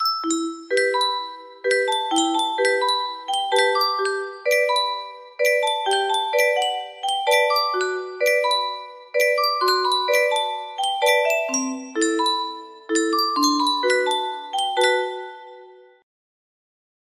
Type Full range 60
BPM 64